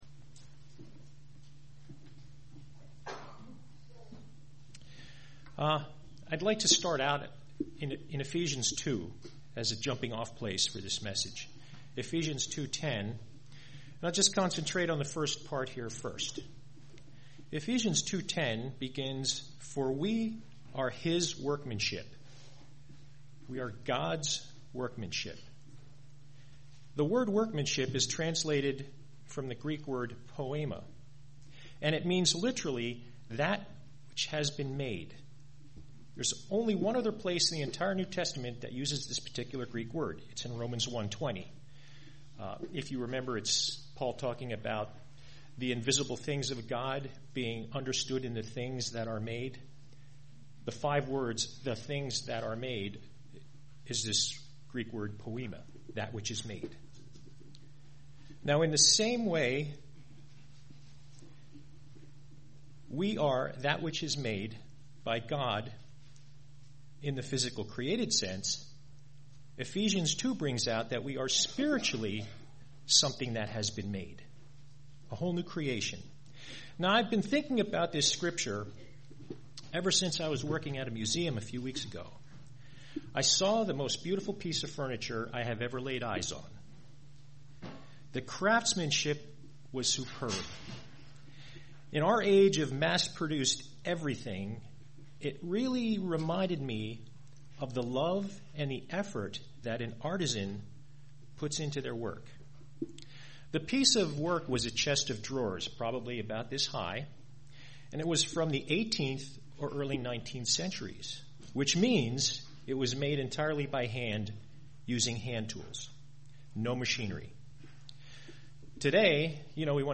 Sermons
Given in Lehigh Valley, PA